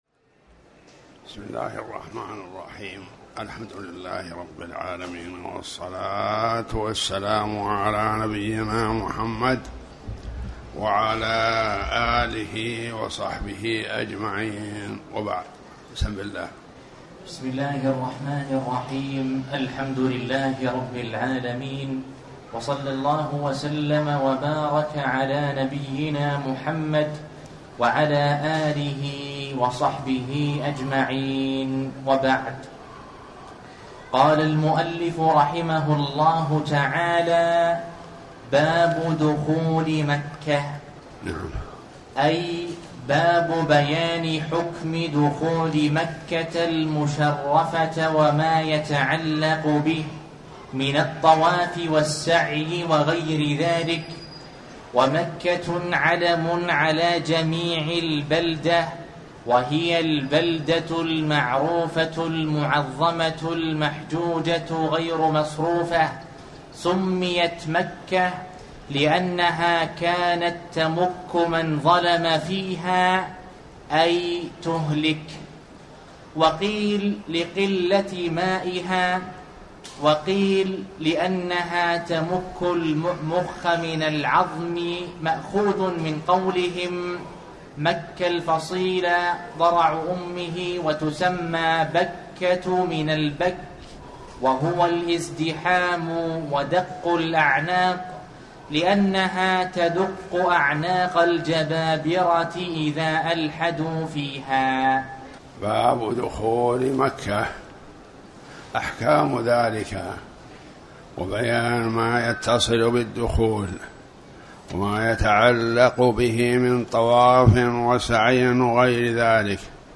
تاريخ النشر ١٢ محرم ١٤٤٠ هـ المكان: المسجد الحرام الشيخ